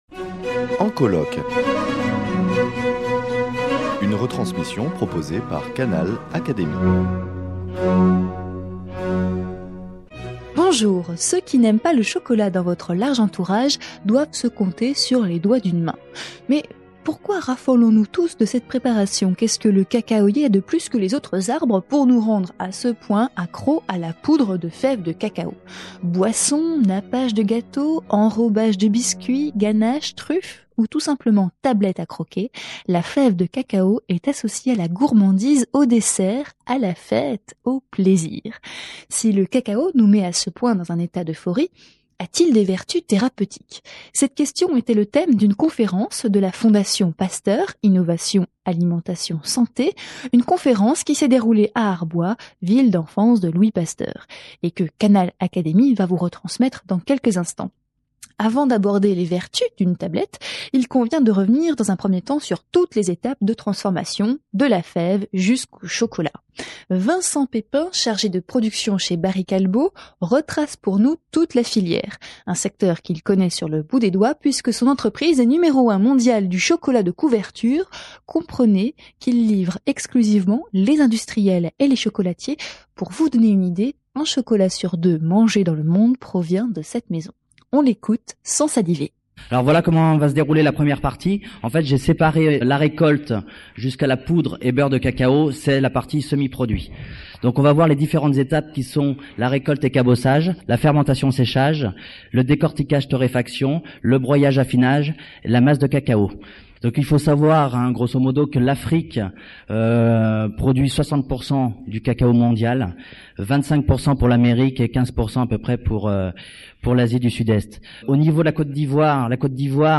Découvrez les vertus pharmaceutiques du chocolat dans cette conférence gourmande ! Organisée par la maison Louis Pasteur (une fondation de l’Académie des sciences), elle s’est déroulée en octobre 2006 au château Pécauld à Arbois (Jura).